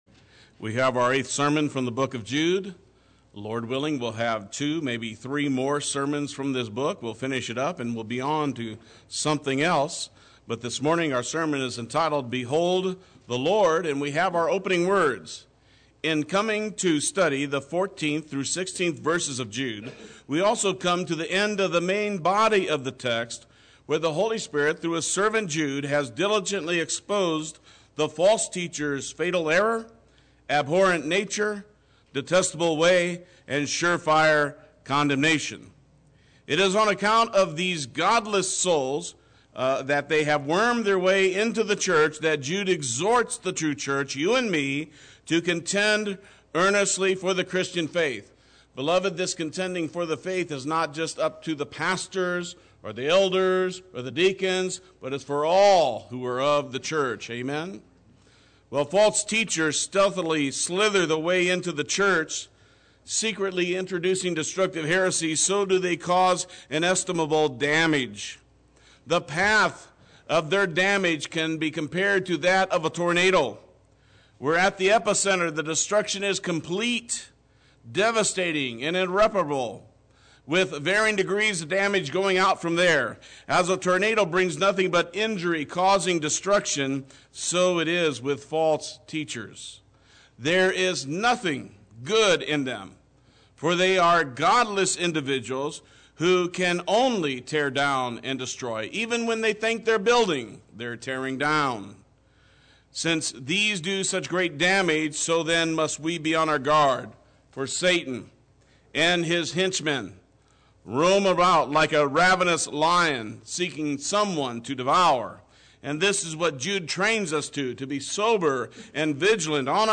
Play Sermon Get HCF Teaching Automatically.
the Lord Sunday Worship